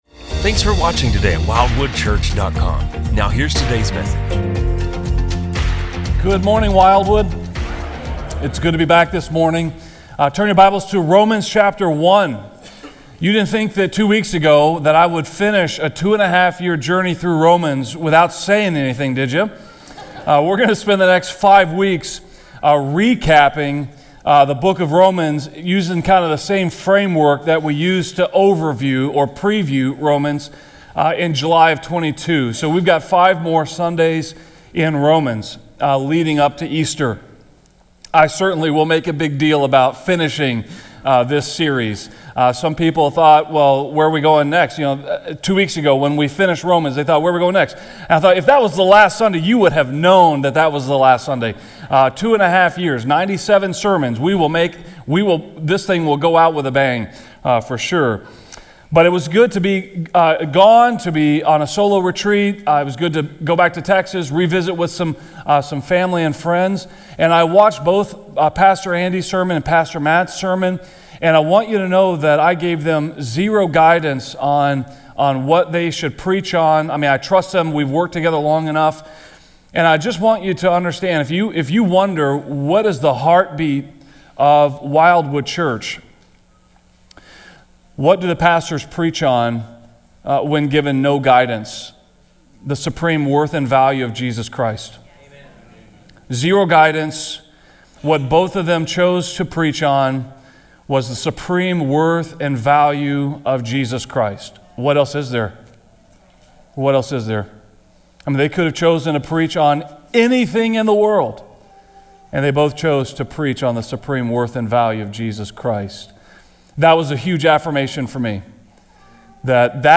The sermon reflects on the transformative power of Romans, a book that has profoundly impacted church history and continues to change lives today. It highlights how Romans reveals humanity’s sinful state, the futility of self-righteousness, and the incredible hope found in Jesus Christ’s redeeming grace. The message calls listeners to abandon sin, trust in Christ, and embrace the righteousness God freely offers through faith.